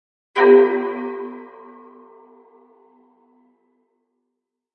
我的声音" 精神病患者的笑声
标签： 恐惧 恐怖 心理 疯狂
声道立体声